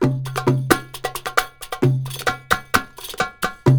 PERC 01.AI.wav